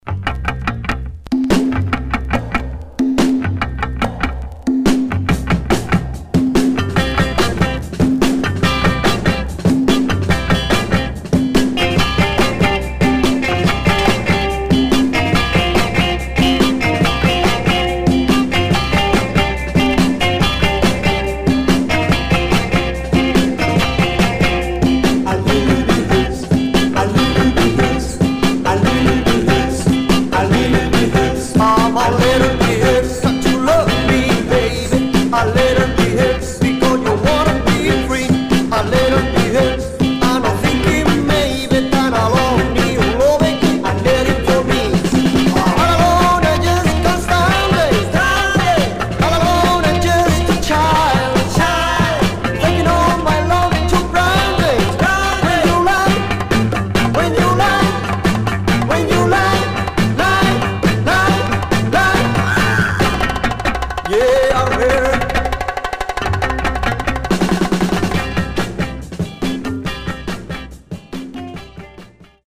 Some surface noise/wear
Mono
Garage, 60's Punk